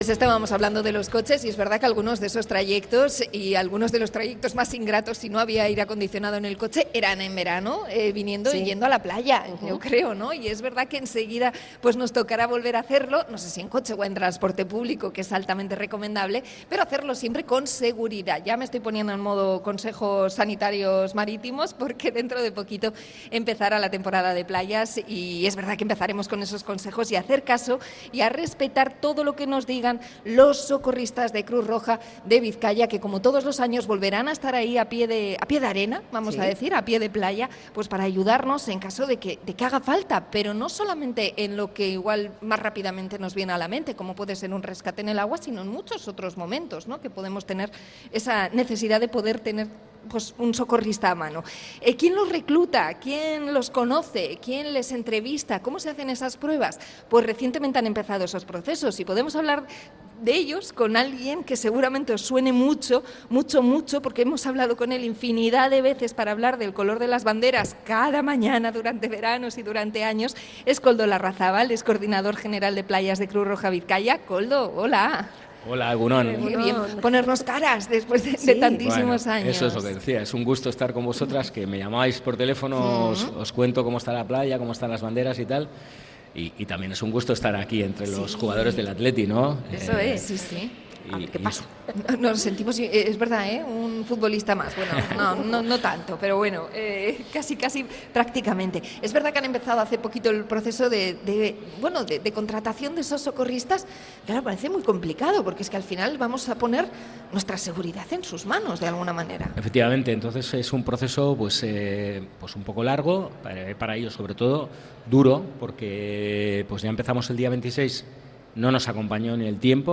Entrevista a Cruz Roja Bizkaia por las pruebas a los socorristas